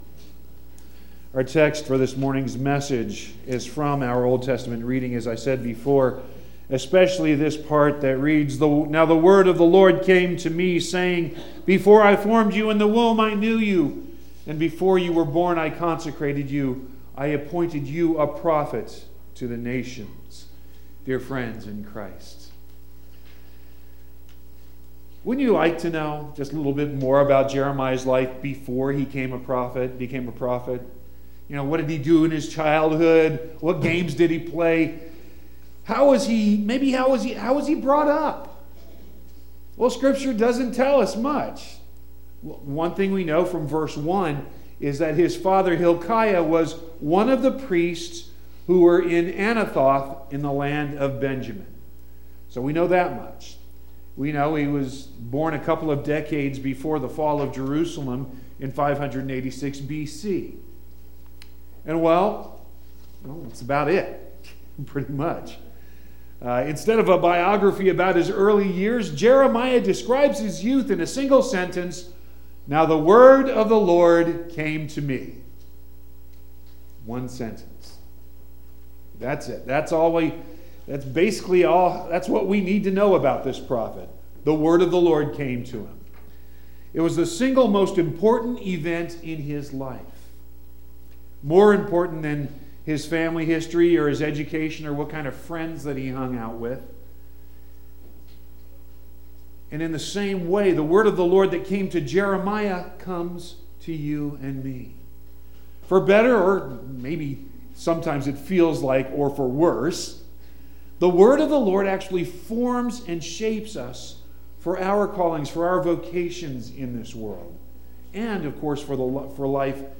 The Fourth Sunday after Epiphany 2.3.19